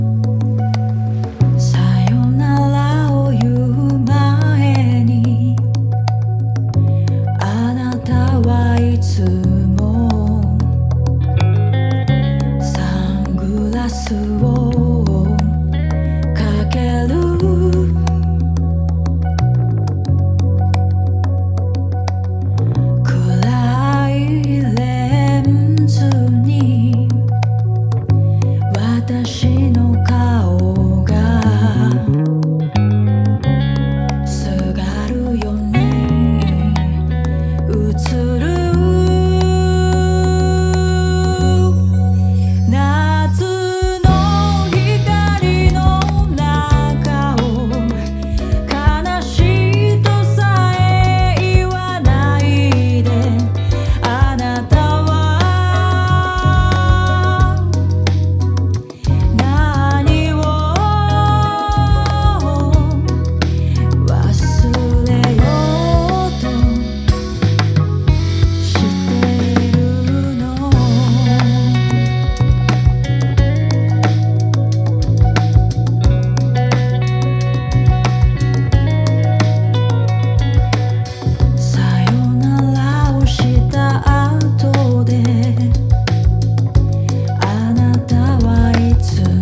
CITY POP / AOR
福岡を拠点に活動するソウルジャズバンド。